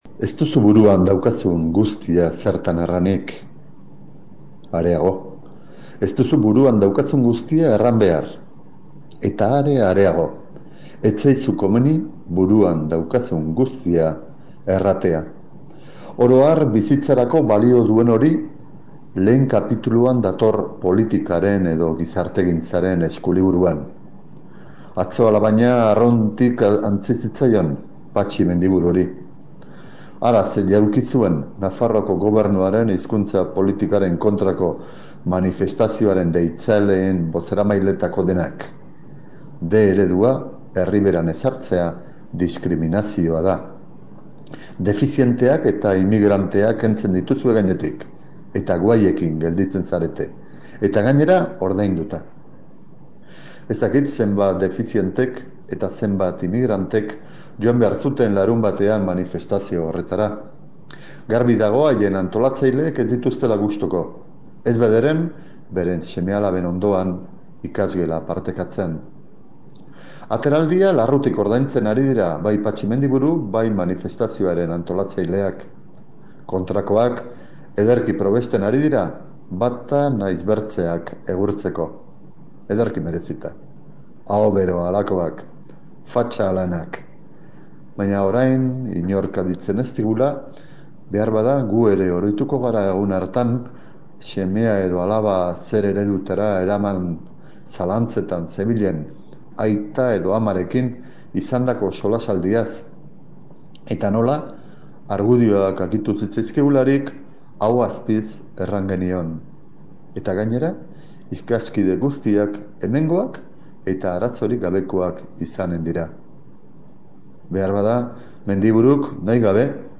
[Euskalerria Irratiko Metropoli Forala saiorako ‘Minutu bateko manifestua’, 2018ko maiatzaren 30ekoa]